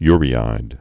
(yrē-īd)